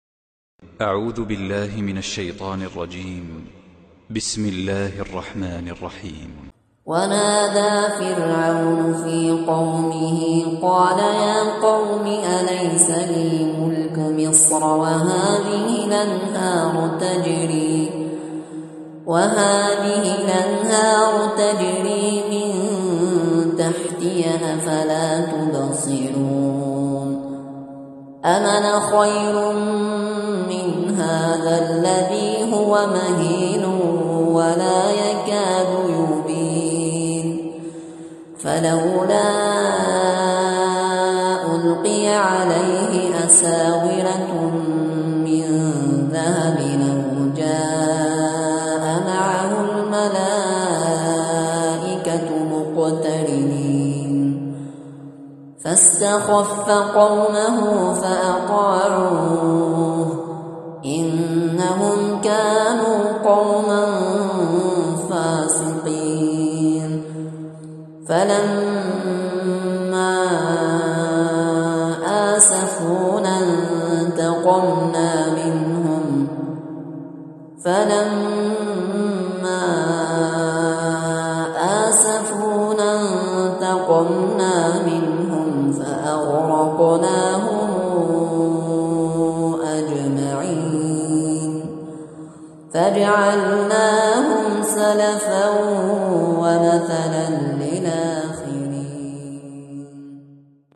تلاوة خاشعة من شاب جزائري
تلاوة خاشعة